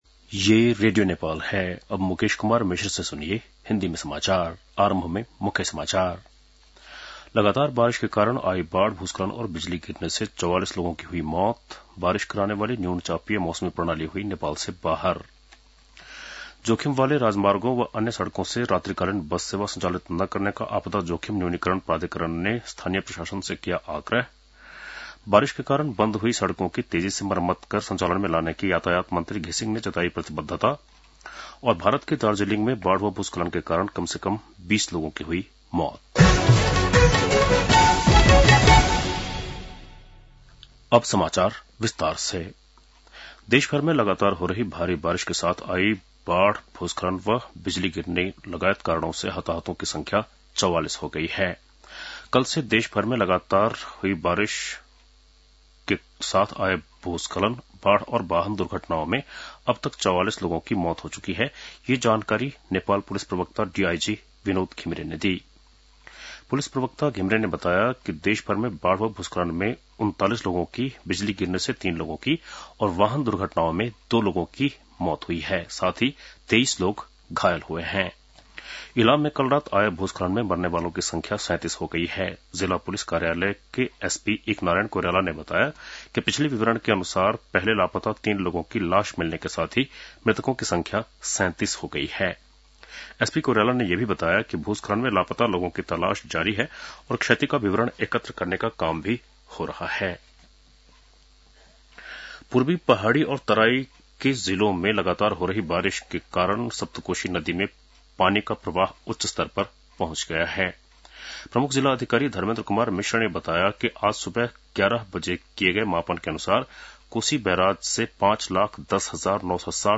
10-pm-hindi-news-6-19.mp3